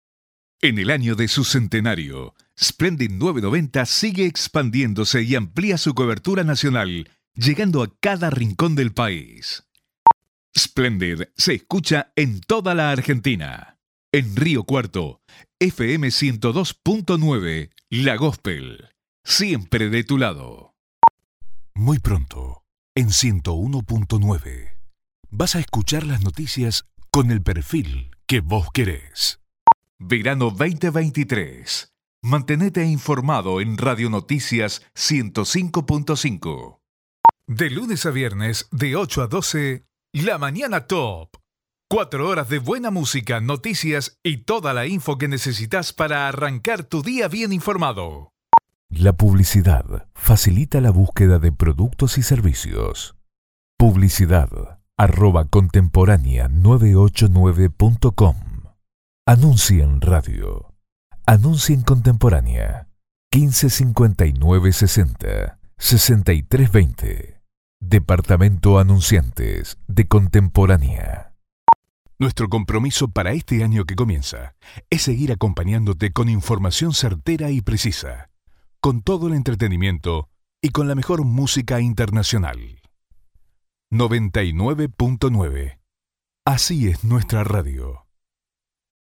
Adult male voice, warm and trustworthy, with clear diction and strong on-mic presence.
Radio / TV Imaging